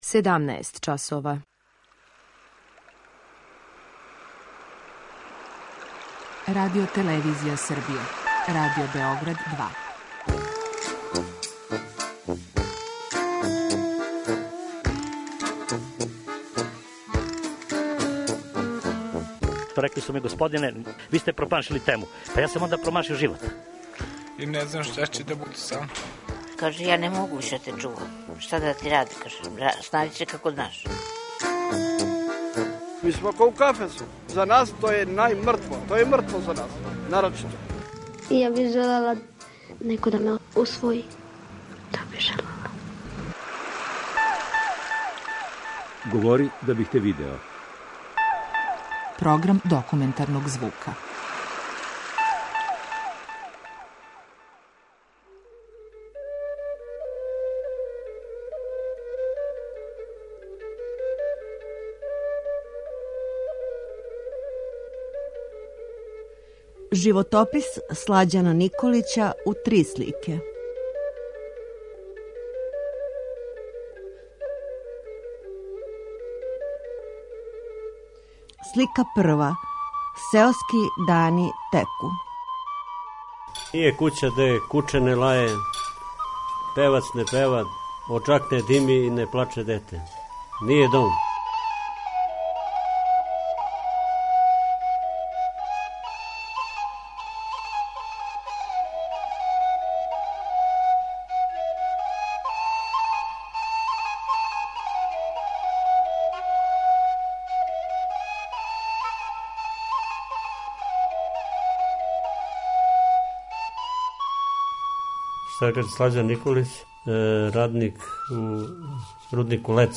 Документарни програм: Живот на сеоски начин